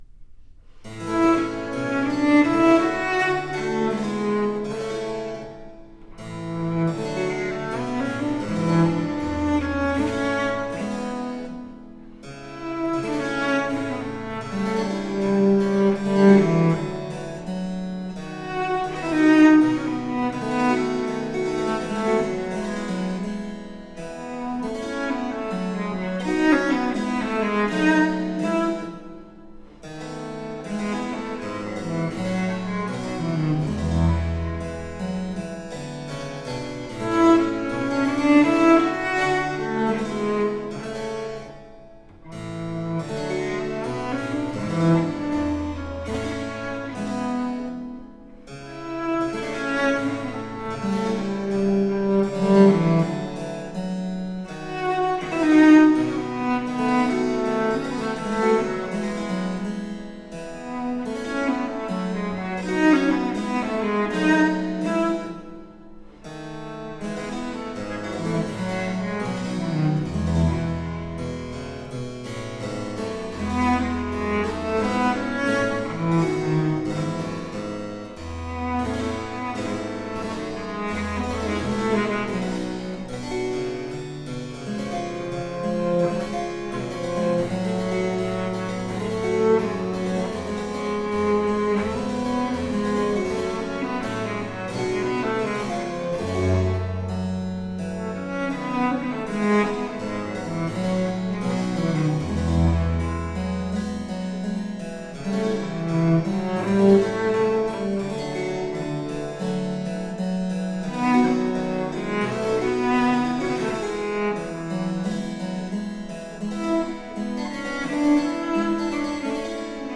Rare and extraordinary music of the baroque.
Classical, Baroque, Instrumental
Harpsichord